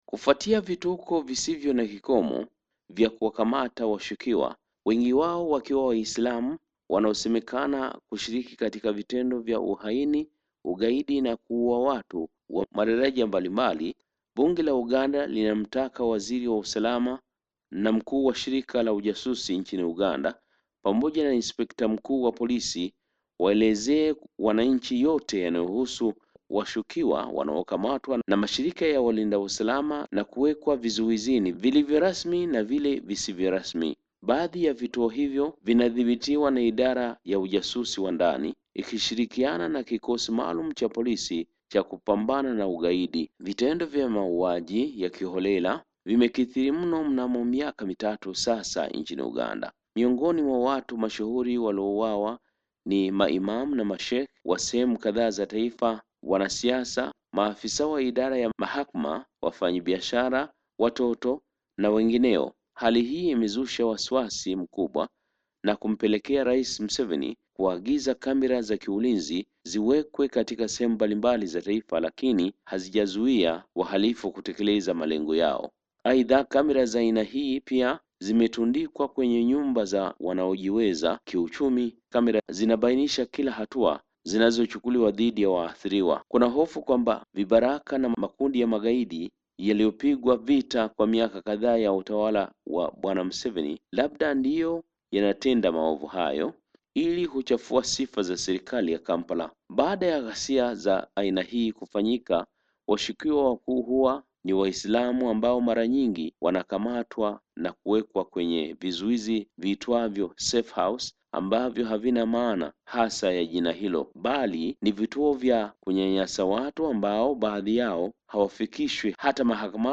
taarifa zaidi kutoka Kampala